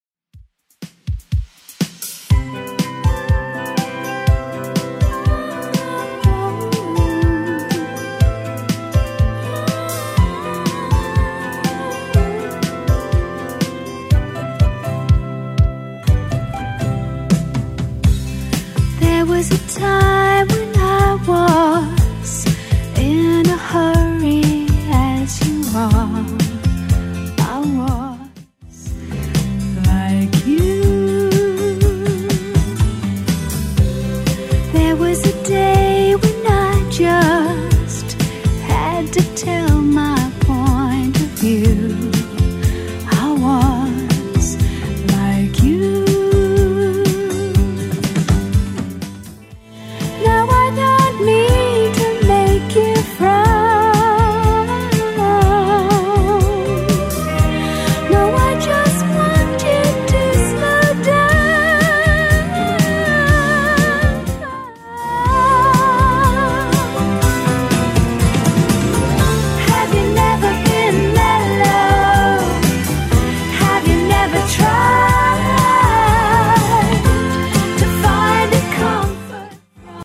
Genre: 80's
BPM: 140